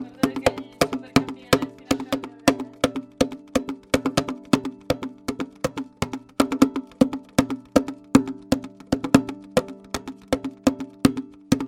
真实的/音乐的/没有旋律的 " 影响小提琴11
描述：小提琴在做拨弦时产生的声音，在演奏时移动手指使音符变得尖锐。 用ZOOM H4单声道录音，用RODE NTG1作为外部麦克风
标签： 奇怪的声音 字符串 非电子效果 小提琴 音符 圆弧 LCS13 旋律